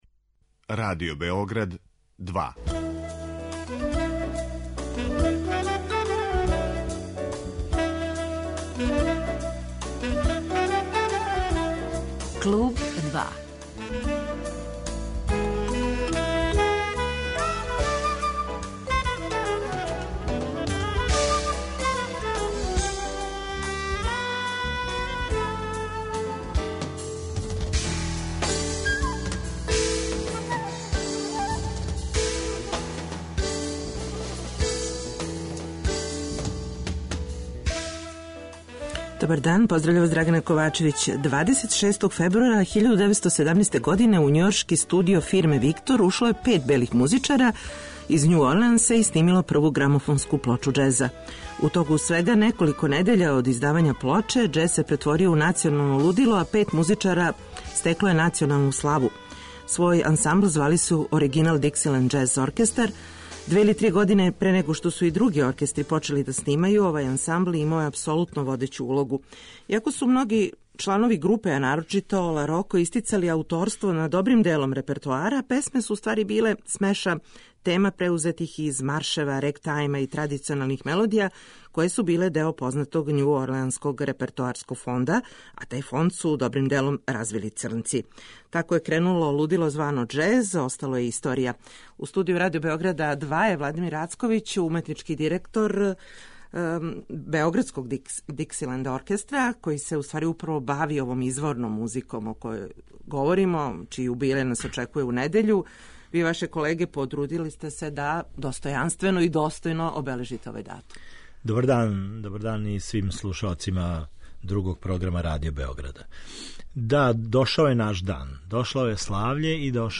Гост емисје